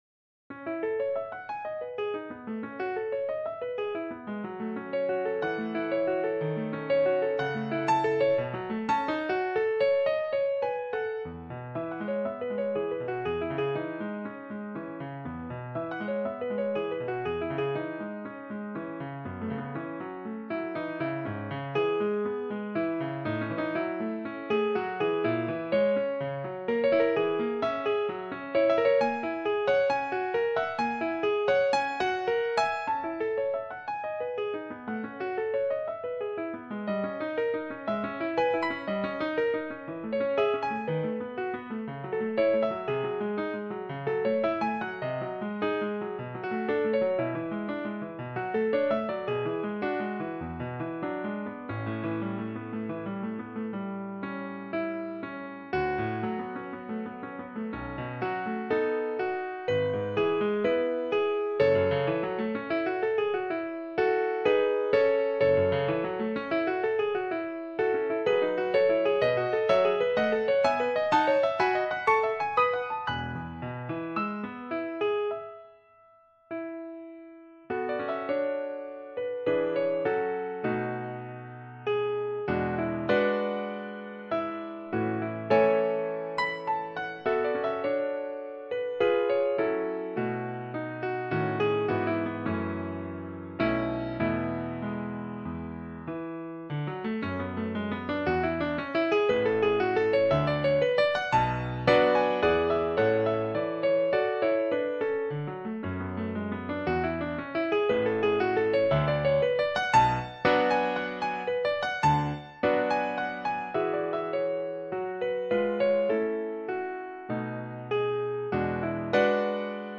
piano pieces
piano sheet music 1st page